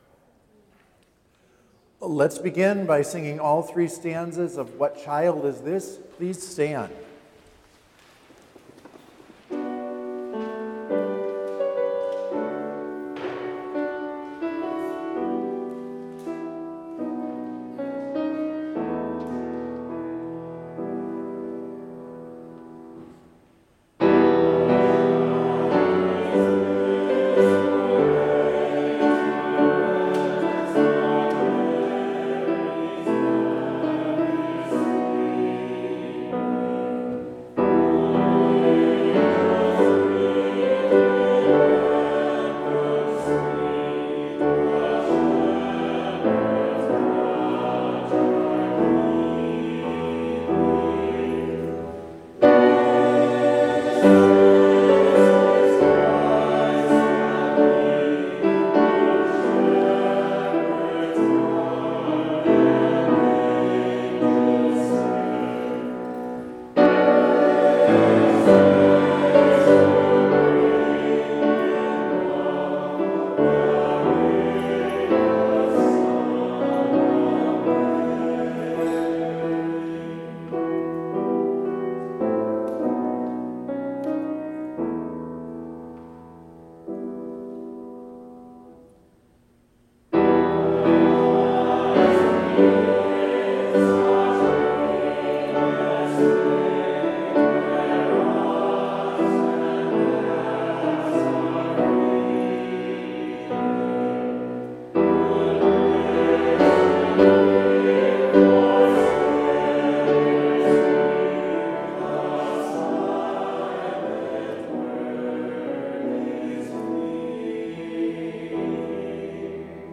Complete service audio for Chapel - March 25, 2021